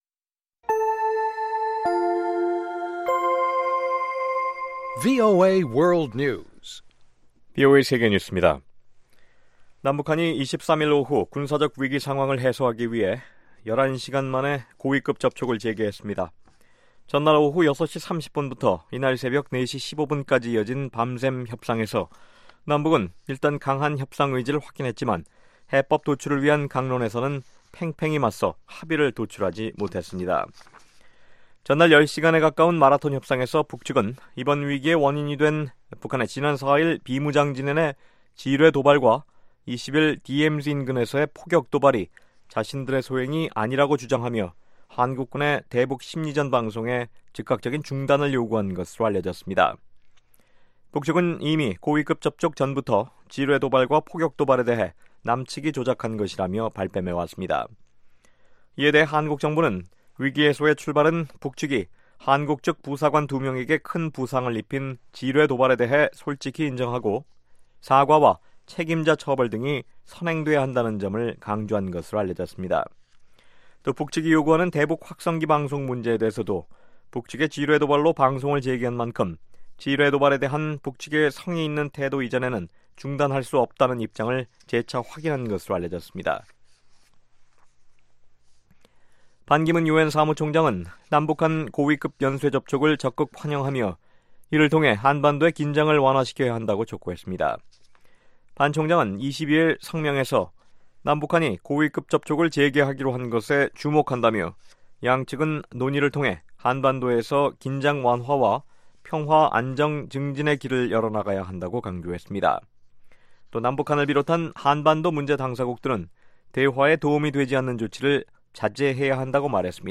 VOA 한국어 방송의 일요일 오후 프로그램 2부입니다.